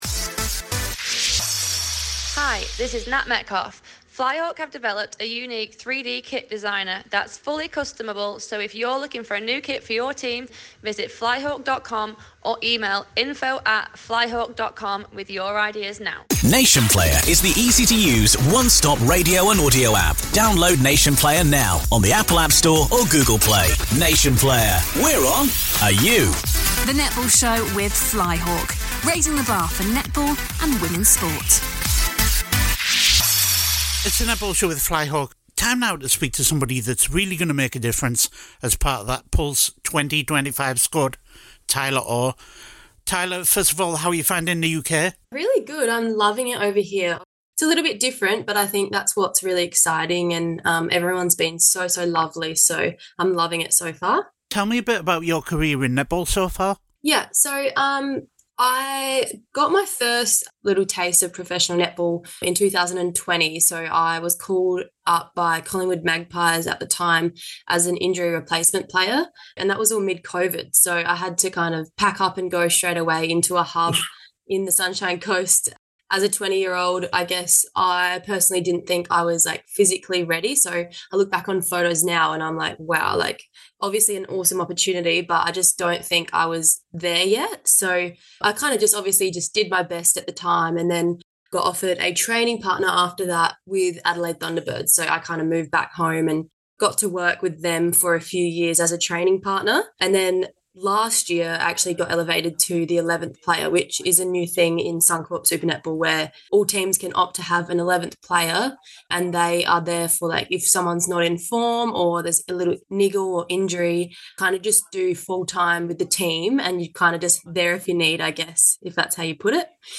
We spoke to her ahead of the 2025 Netball Superleague season.